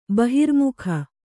♪ bahirmukha